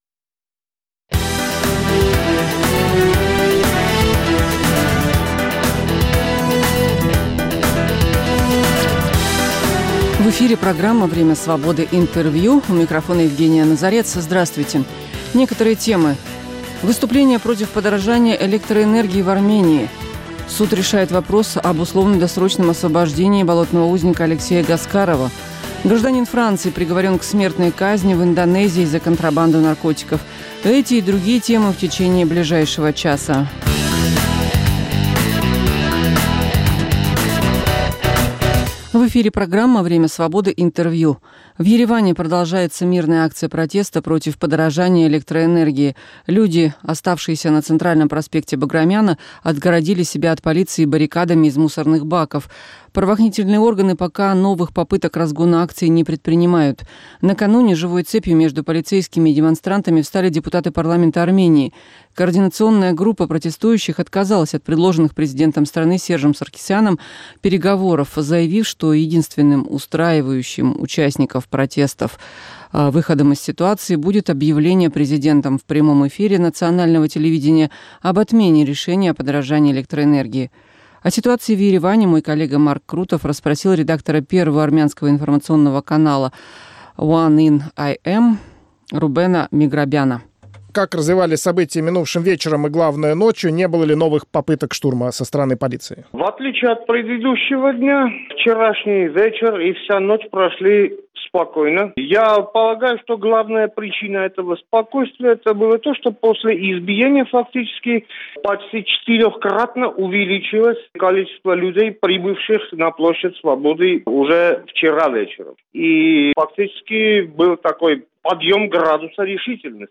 Время Свободы - Интервью